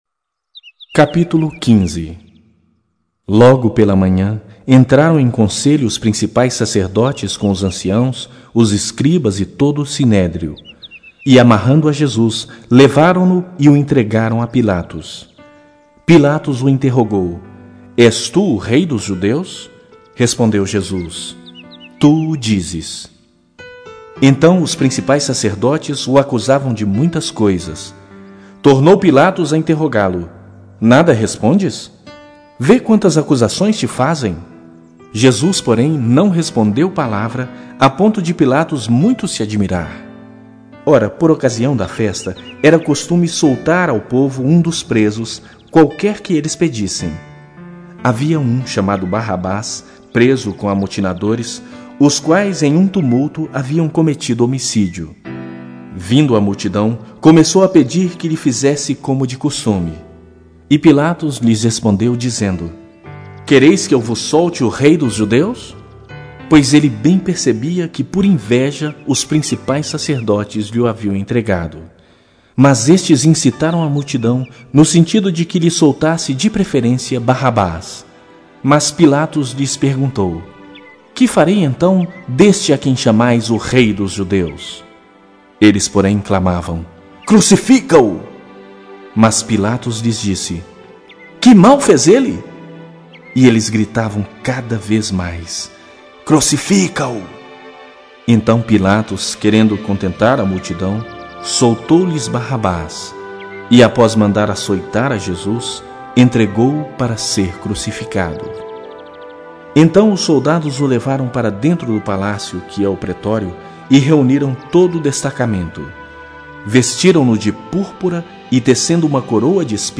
Bíblia Sagrada Online Falada
Versão Bíblia Falada Ministério (?)